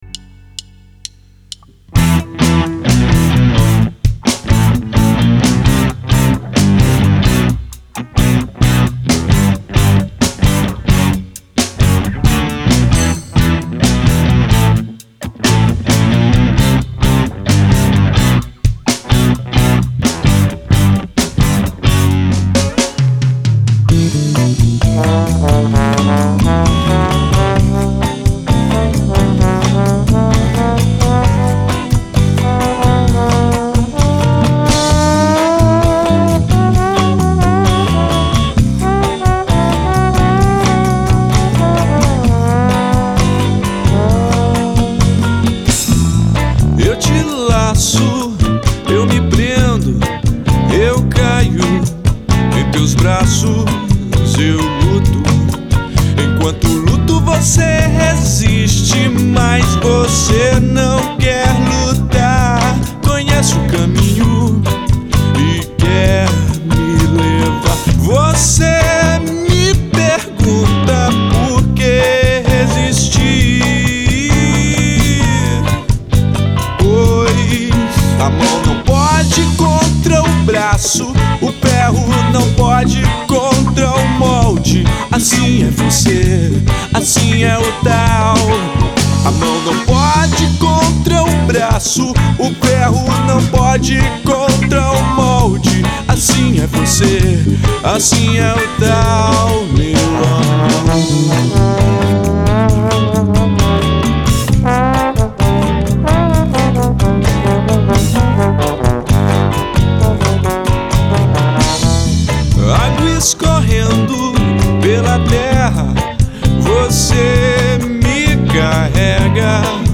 2707   03:32:00   Faixa: 2    Rock Nacional